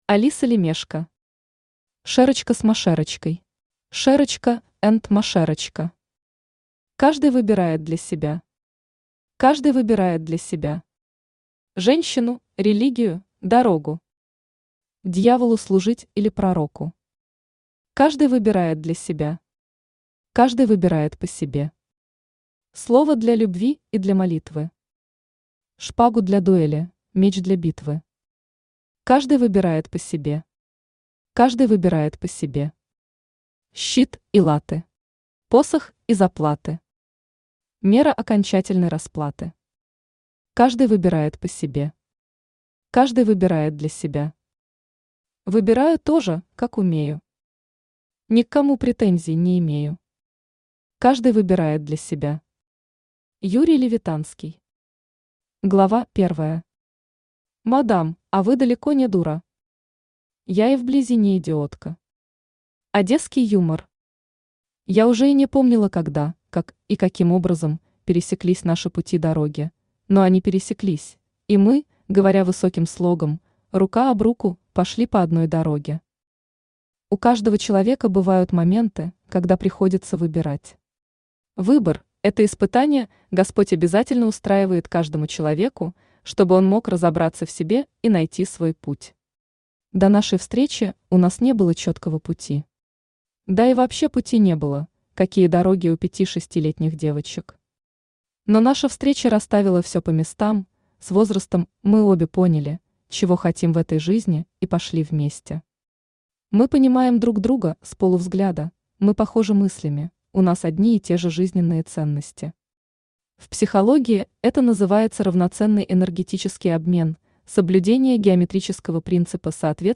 Aудиокнига Шерочка с Машерочкой Автор Алиса Лемешко Читает аудиокнигу Авточтец ЛитРес.